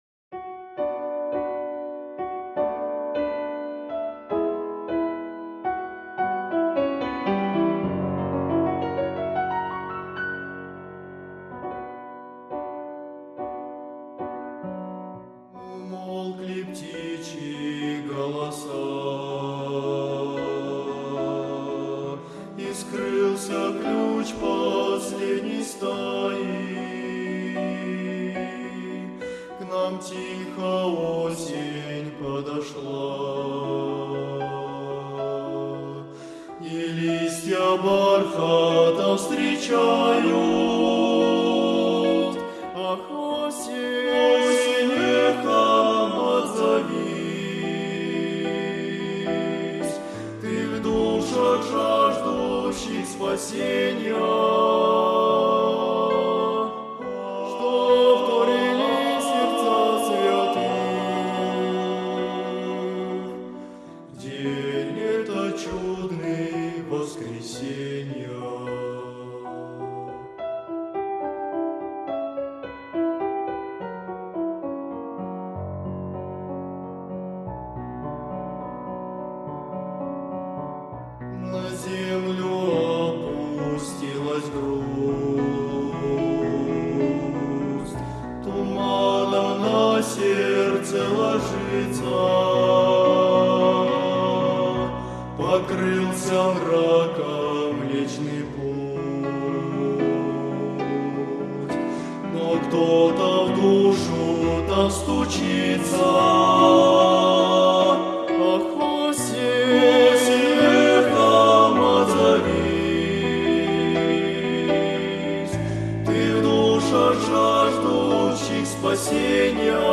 189 просмотров 264 прослушивания 9 скачиваний BPM: 160